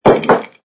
sfx_put_down_glass03.wav